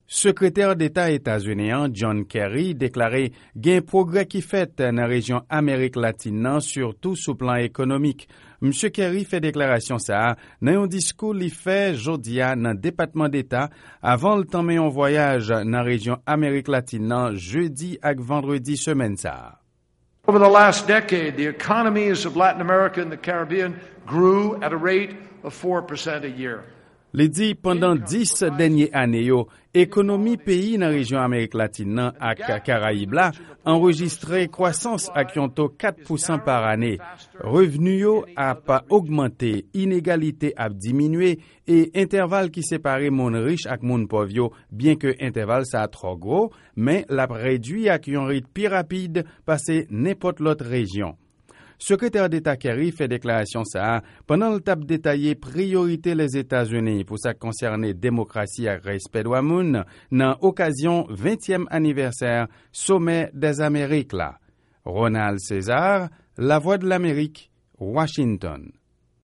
Mesaj Sekretè Deta John Kerry sou 20èm Anivèsè Somè dè Zamerik la.